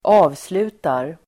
Uttal: [²'a:vslu:tar]